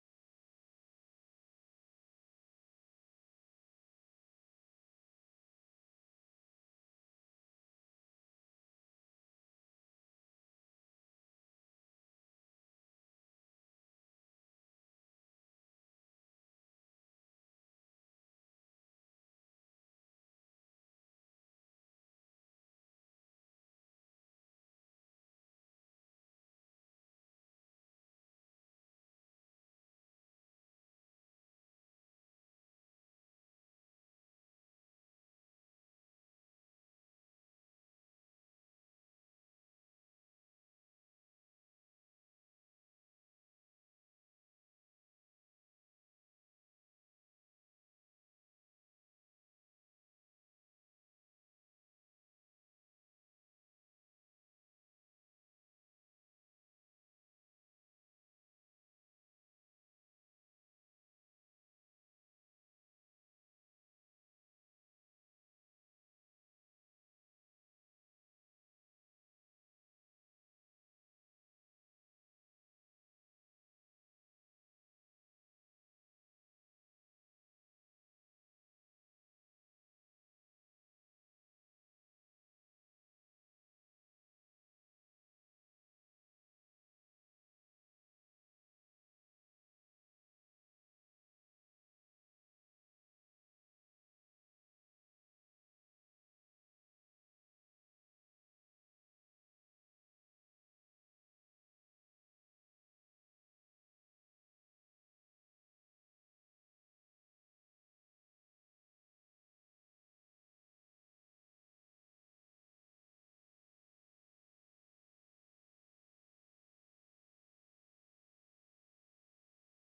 تاريخ النشر ١١ جمادى الأولى ١٤٤٠ هـ المكان: المسجد الحرام الشيخ